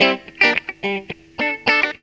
GTR 67.wav